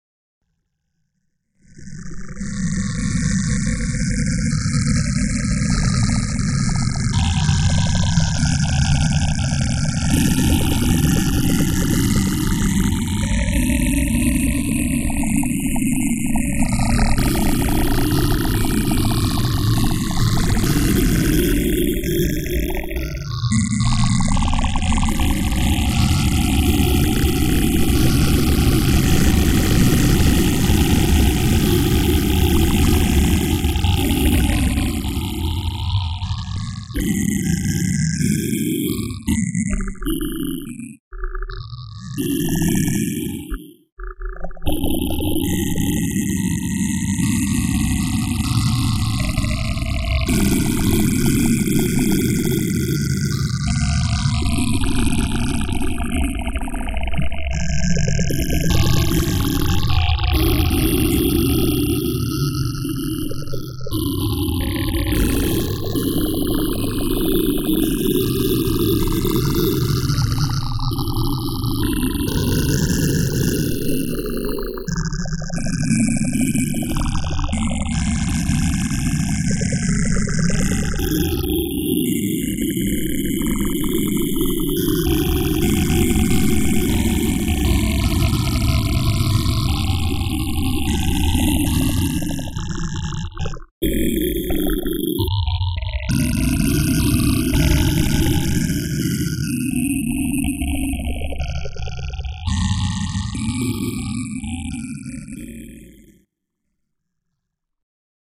these files are really just the scraps that had accumulated after producing the three compositions for the layer project. i couldn't let them go, gathered them together and am presenting them here as a collection of sound points.